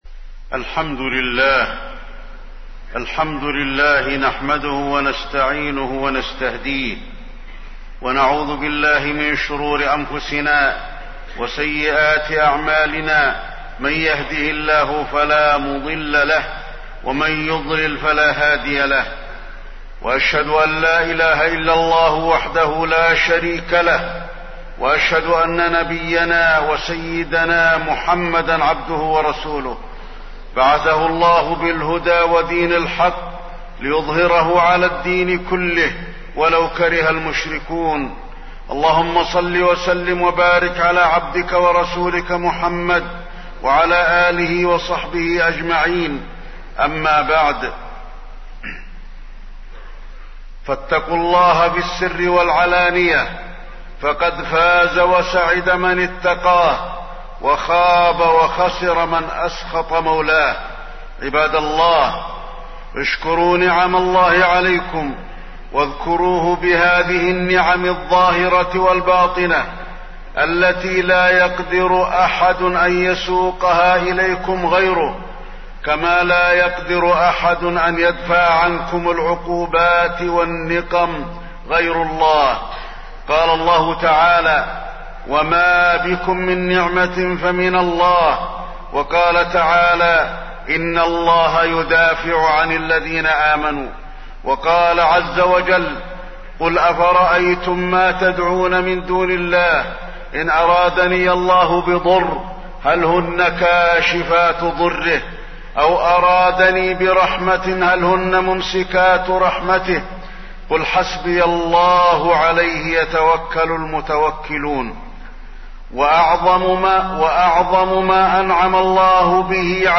تاريخ النشر ٦ شوال ١٤٣٠ هـ المكان: المسجد النبوي الشيخ: فضيلة الشيخ د. علي بن عبدالرحمن الحذيفي فضيلة الشيخ د. علي بن عبدالرحمن الحذيفي العمل الصالح بعد رمضان